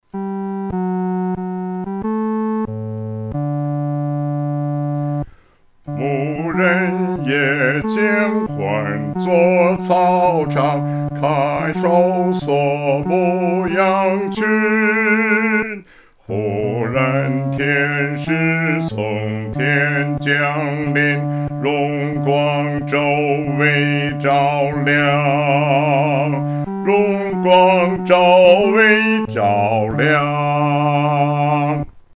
独唱（第四声）
牧人闻信-独唱（第四声）.mp3